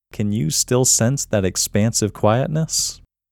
QUIETNESS Male English 19
The-Quietness-Technique-Male-English-19.mp3